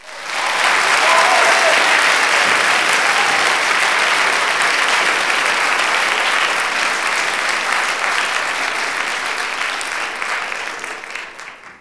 clap_028.wav